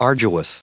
78_arduous.ogg